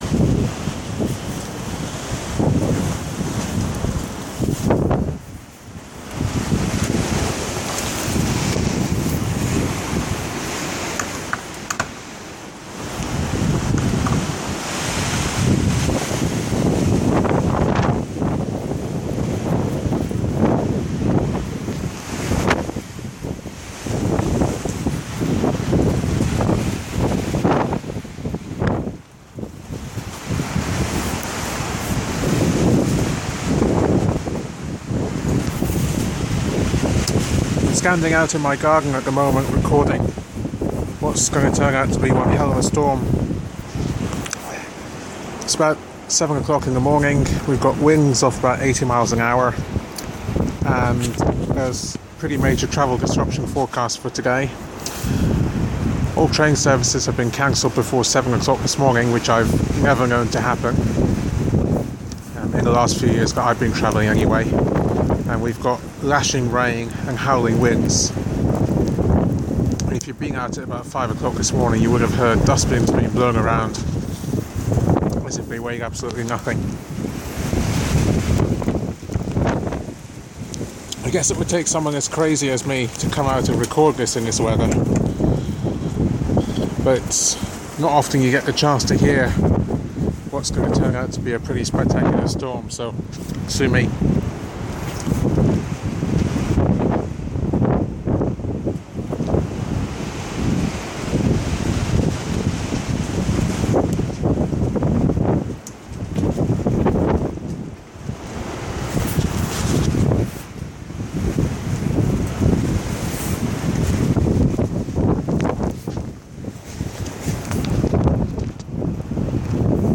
Listen to the wild storm sweeping Scotland. Recorded 07:00 04 Dec 2013 at Portlethen, Aberdeenshire.